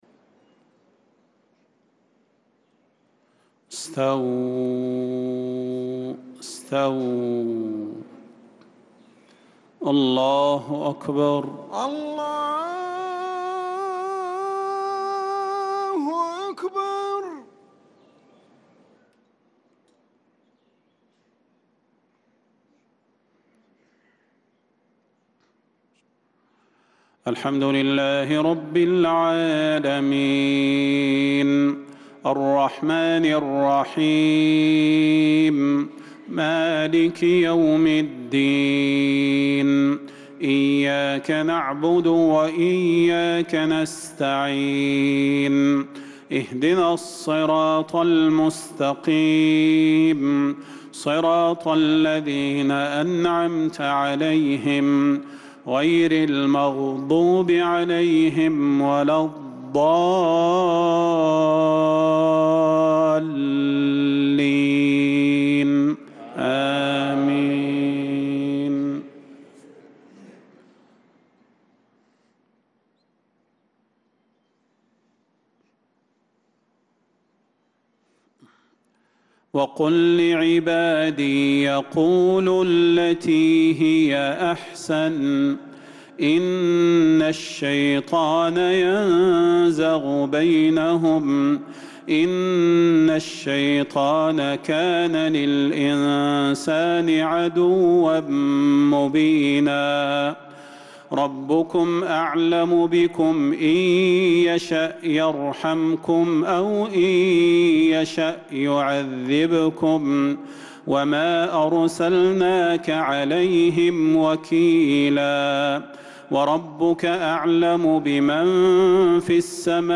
صلاة المغرب للقارئ صلاح البدير 22 ذو القعدة 1445 هـ
تِلَاوَات الْحَرَمَيْن .